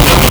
Player_Glitch [28].wav